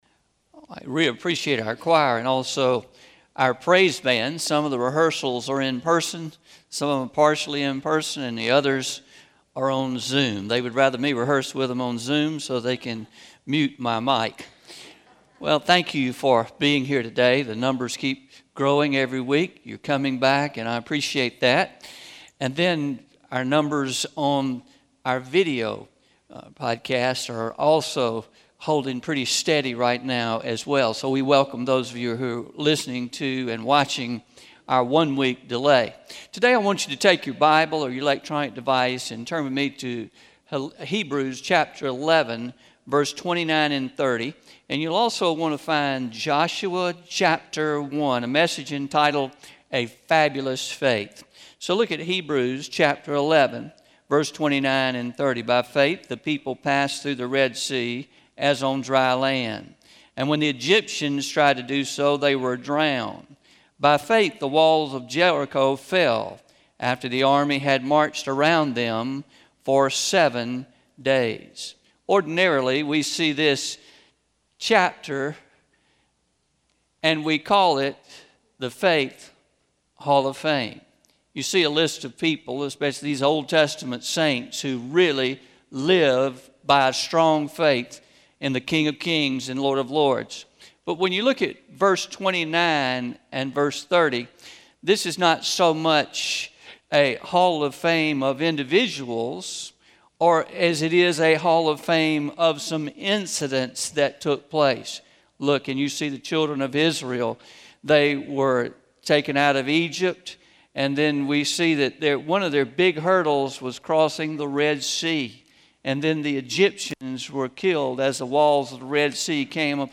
Traditional – 03-07-21am Sermon – A Fabulous Faith